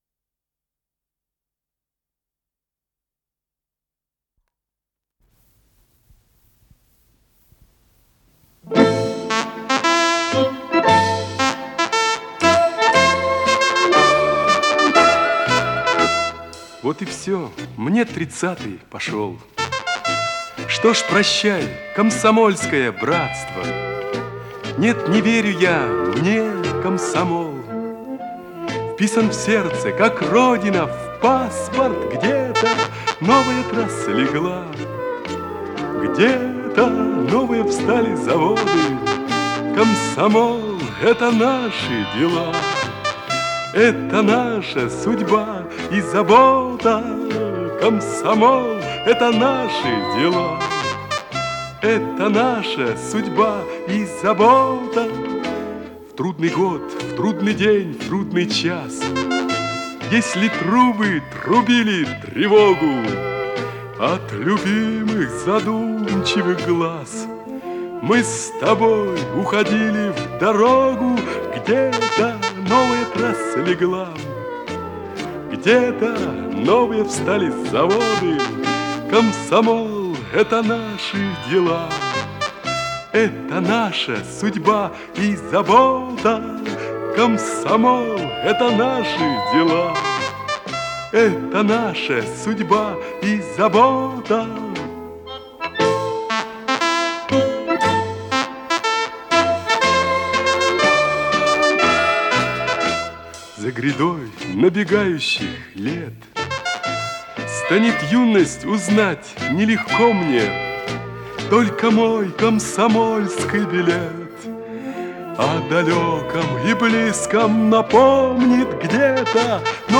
с профессиональной магнитной ленты
ВариантМоно